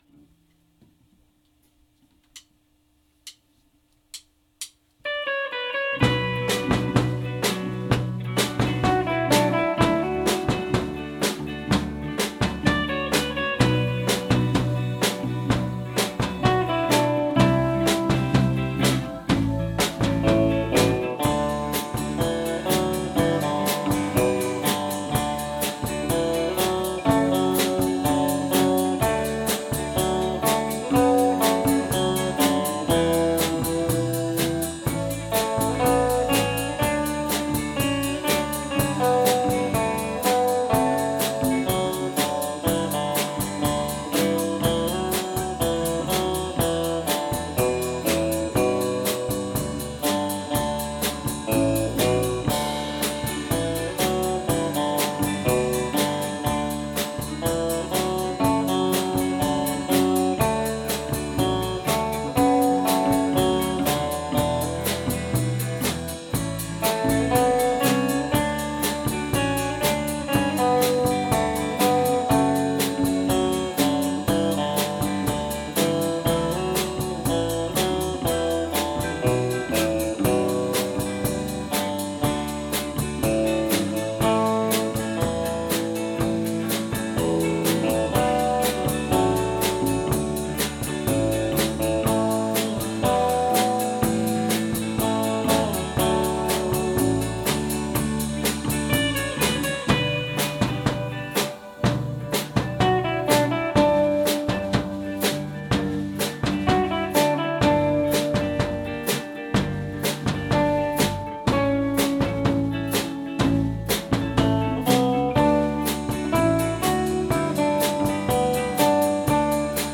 2019 Spring LIve-1 | The Arou Can